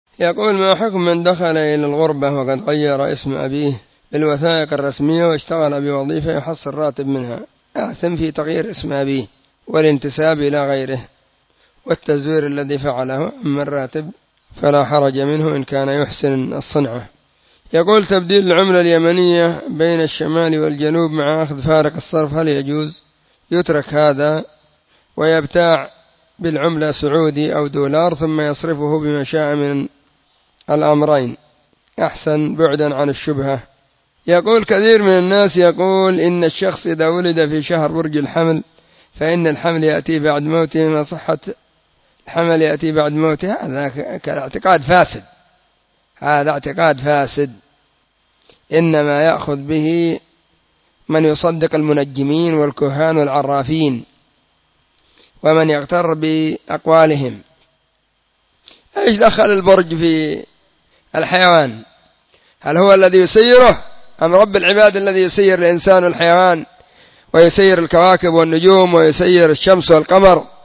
سلسلة الفتاوى الصوتية